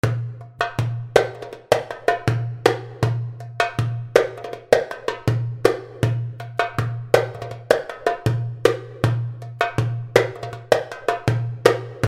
derbouka.mp3